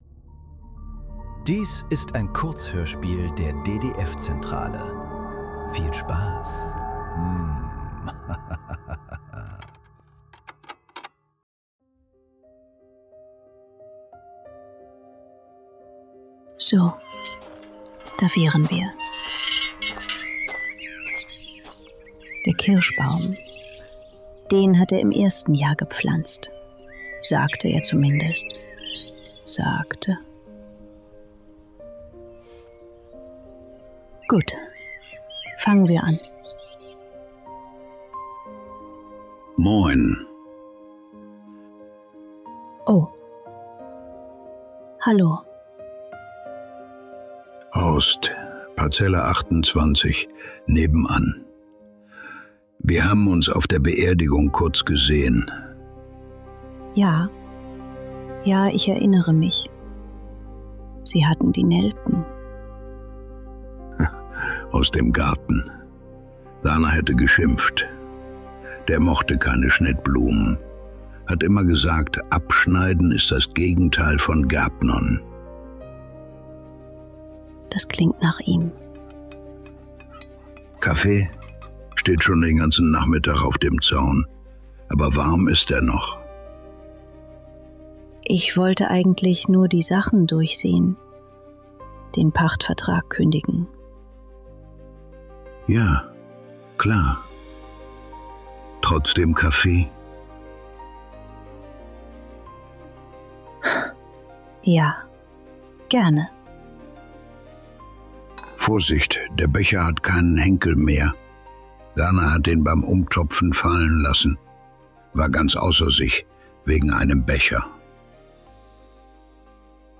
Parzelle 27 ~ Nachklang. Kurzhörspiele. Leise.